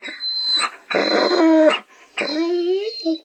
bdog_die_1.ogg